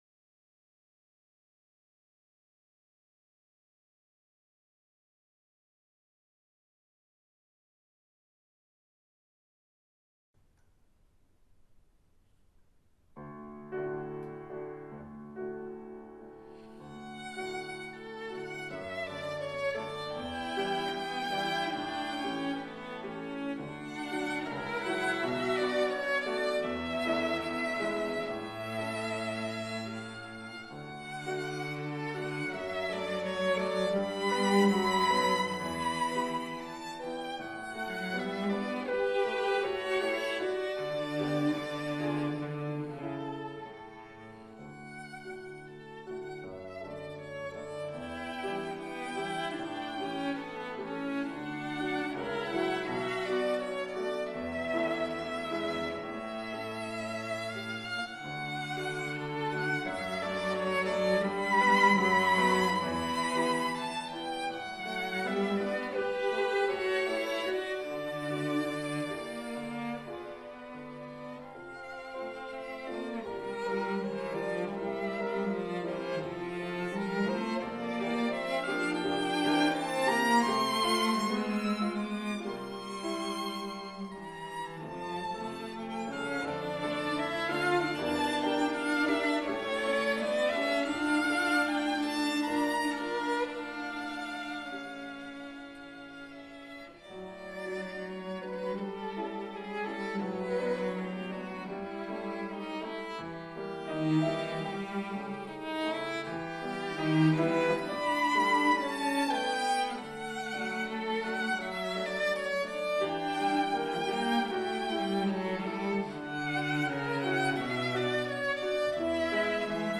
Violin with label „G.A. Chanot “
This violin is an English violin, probably from the Chanot workshop The violin has been played extensively for many years, and its sound has developed excellently. As clearly discernible in the audio sample, the instrument produces a clear, balanced sound that blends very well with other instruments
Edward Elgar, Salut dámour, played on this violin.